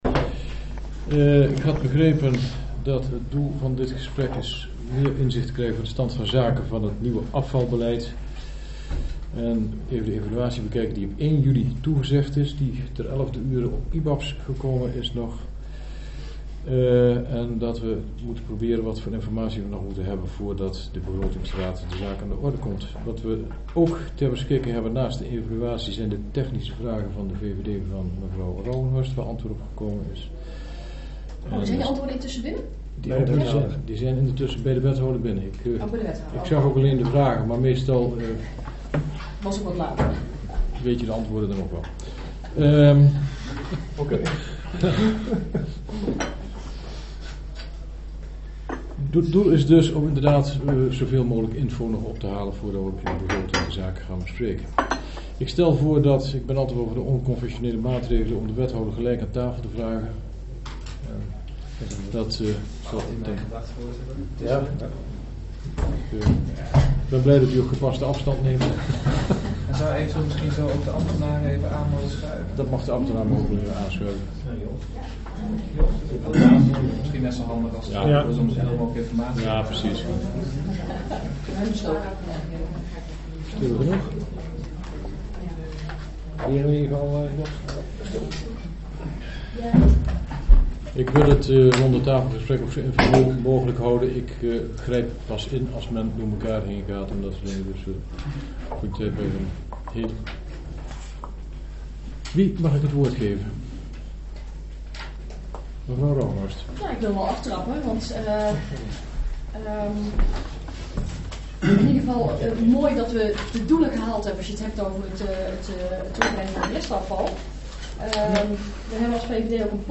Elster Toren S02, gemeentehuis Elst